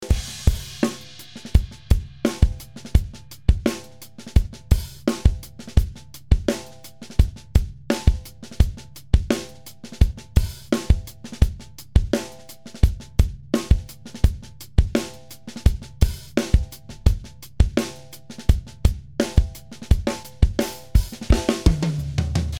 左がPro-Q 3のダイナミックEQ、右がPro-Q 4のスペクトラルダイナミクスを使ったダイナミックEQです。